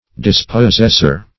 Dispossessor \Dis`pos*sess"or\, n.